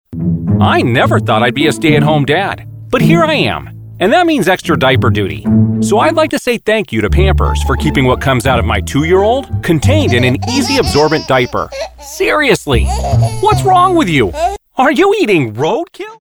Male
English (North American), English (Neutral - Mid Trans Atlantic)
The rich depth of my voice instills confidence and trust.
Pampers Commercial
1207Pampers_commercial.mp3